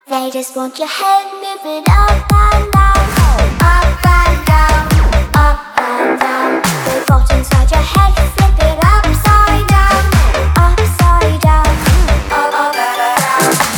• Качество: 321, Stereo
ритмичные
EDM
Trance
детский голос
psy-trance
Hard dance
Melbourne Bounce